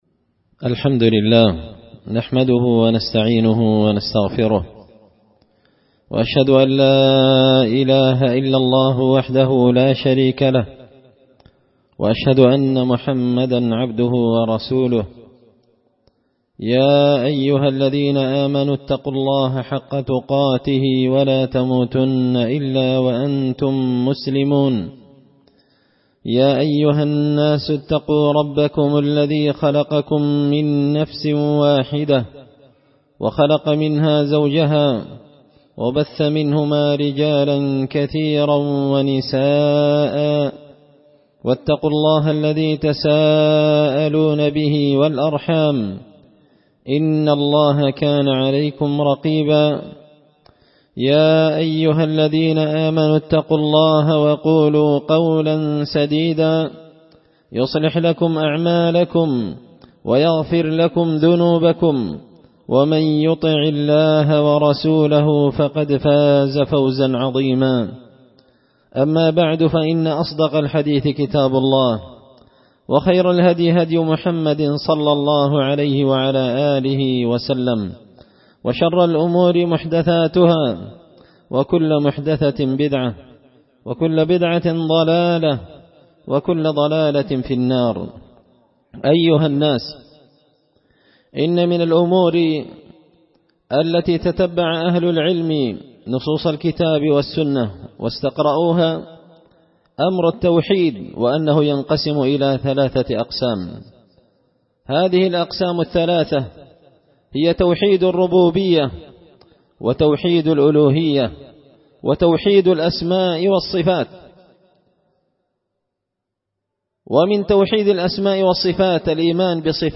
خطبة جمعة بعنوان – الحذر من غضب الله
دار الحديث بمسجد الفرقان ـ قشن ـ المهرة ـ اليمن